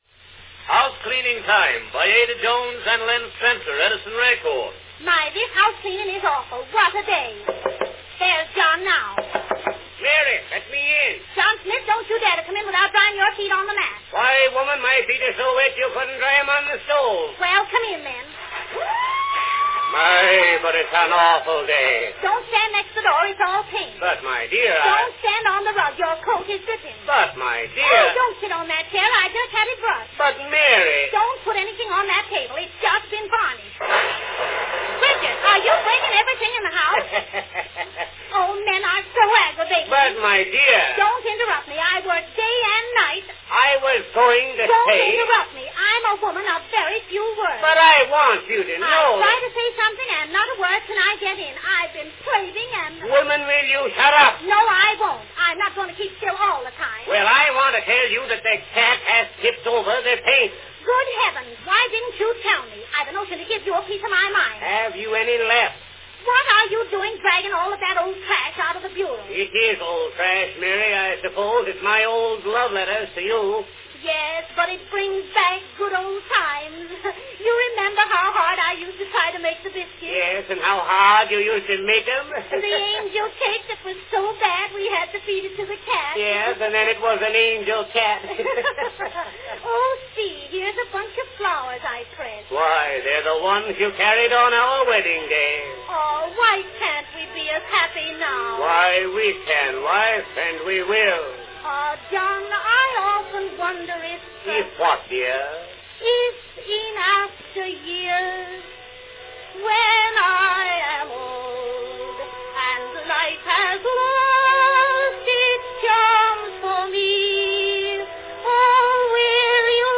Company Edison's National Phonograph Company
Category Vaudeville sketch
Performed by Ada Jones & Len Spencer
Announcement "House Cleaning Time, by Ada Jones & Len Spencer.   Edison record."
Ada Jones and the "lady behind the broom" is as peevish as the occasion demands, even if she does say she is "A woman of few words."
A tender bit of pathos is added as Mary sings: "In After Years When I Am Old."